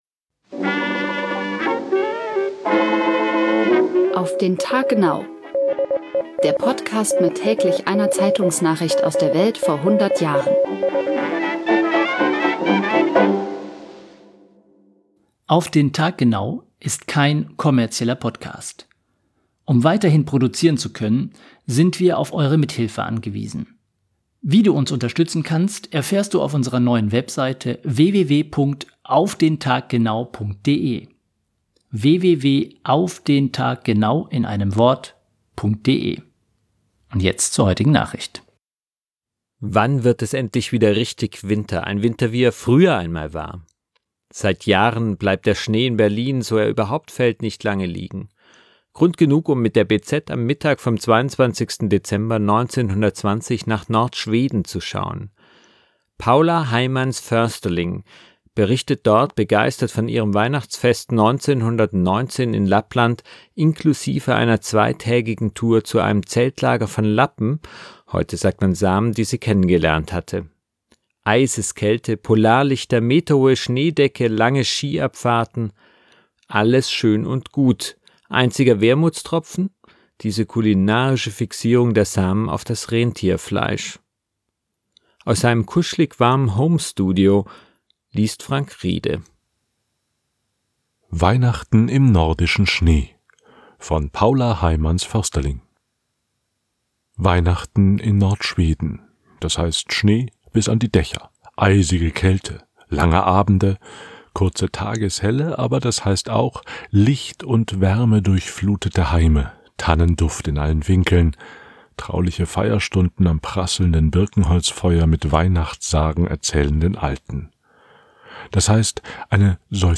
Aus seinem kuschelig warmen Home-Studio liest